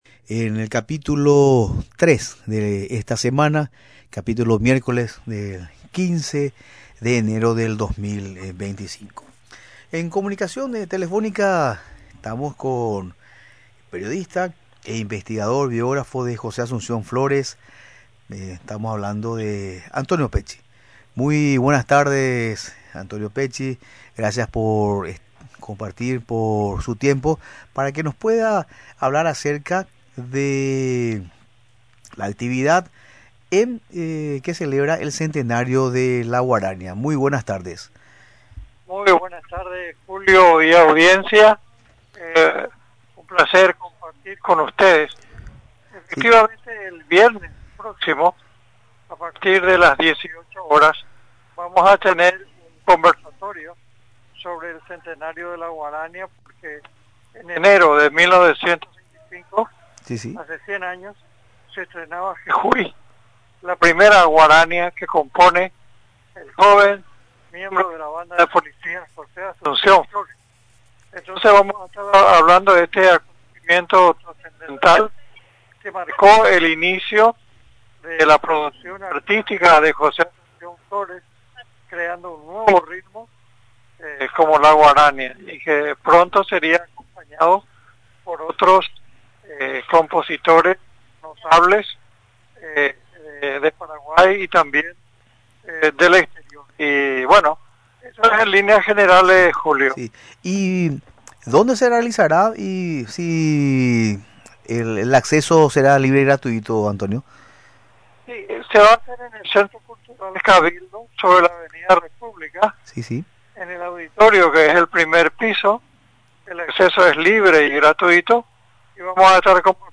Durante la entrevista en Radio Nacional del Paraguay, informó que el evento se realizará mañana viernes 17 de enero, desde las 18:00 horas.